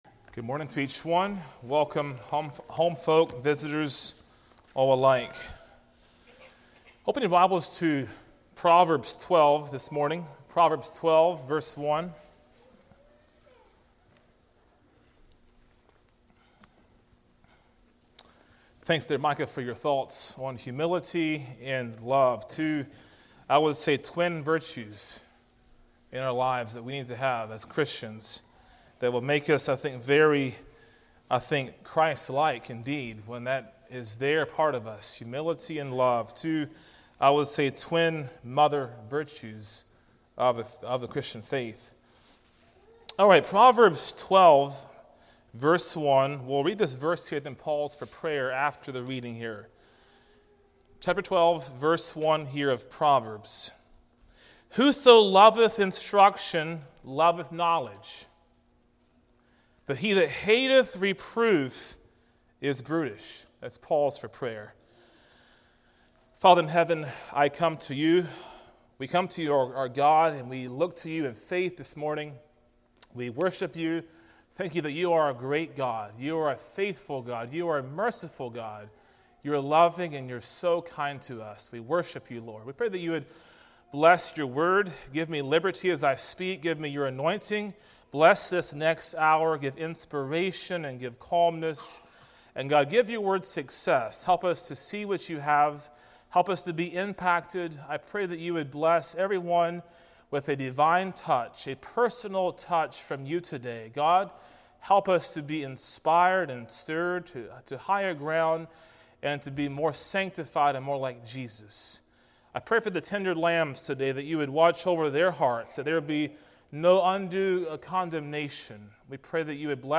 Sermons - Blessed Hope Christian Fellowship